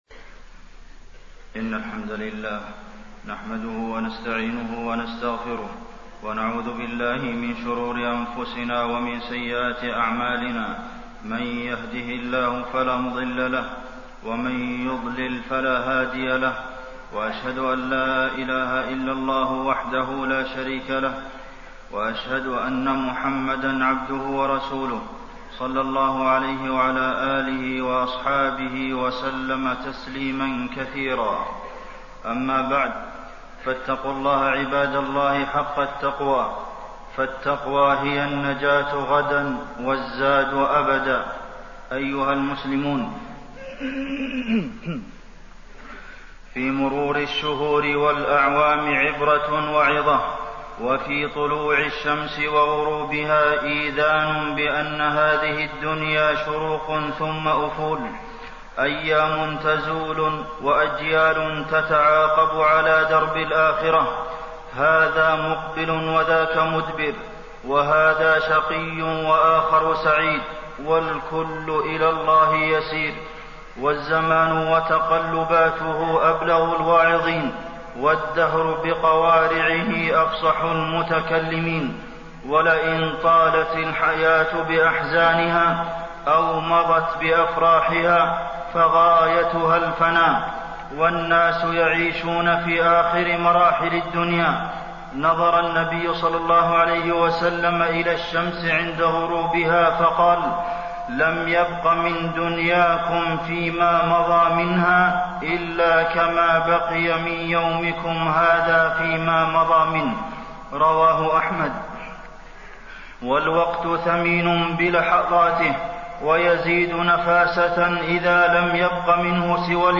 تاريخ النشر ١٤ محرم ١٤٣٣ هـ المكان: المسجد النبوي الشيخ: فضيلة الشيخ د. عبدالمحسن بن محمد القاسم فضيلة الشيخ د. عبدالمحسن بن محمد القاسم الوقت وأهميته في حياة المسلم